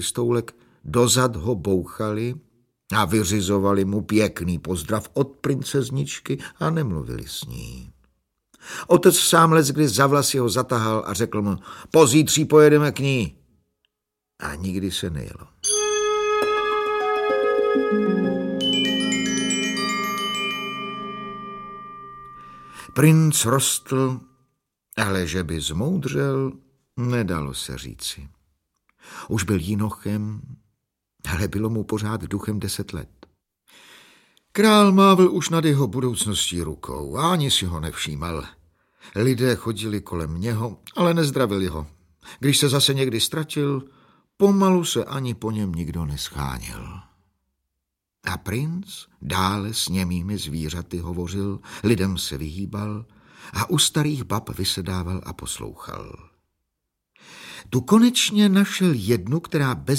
Audiobook
Read: Ladislav Mrkvička